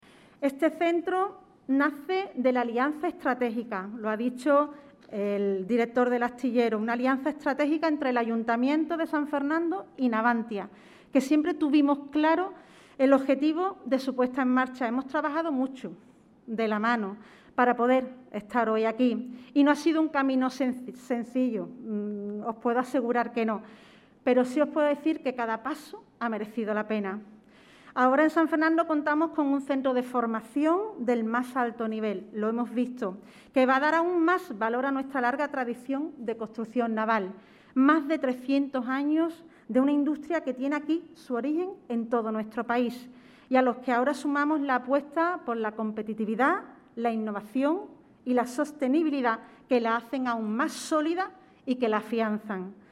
La alcaldesa de San Fernando no ha dudo en recordar la complejidad que ha tenido la puesta en marcha de esta singladura.
YDRAY-YDRAY-ALCALDESA-SAN-FERNANDO.mp3